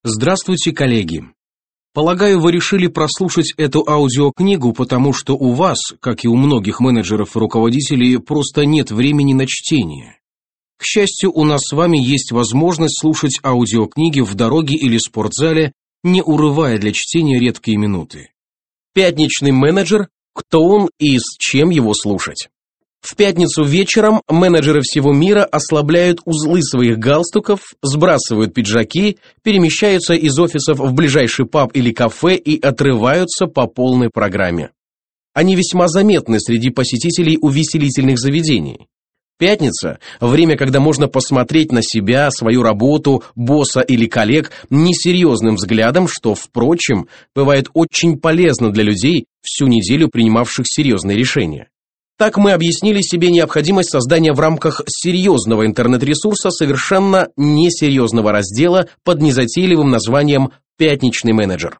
Аудиокнига Пятничный менеджер | Библиотека аудиокниг